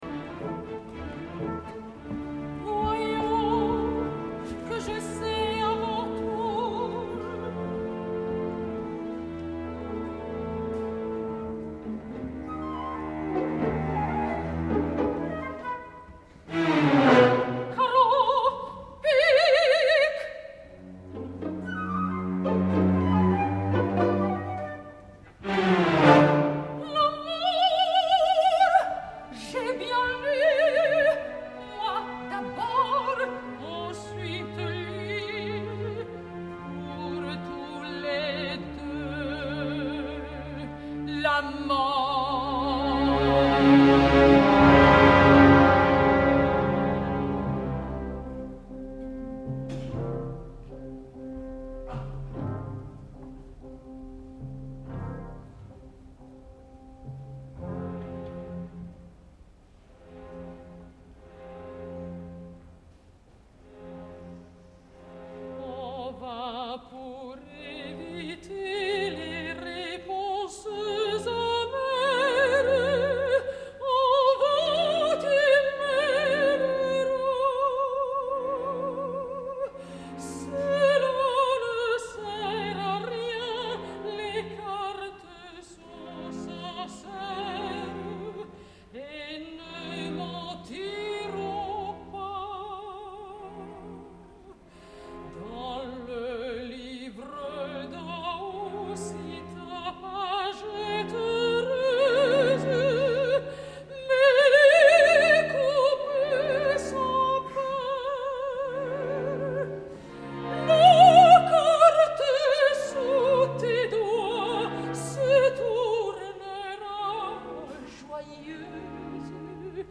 L’acompanyament del director anglès és climàtic, suggerent i inquietant.
Salle Favart, l’Opéra Comique, Paris, 30 de juny de 2009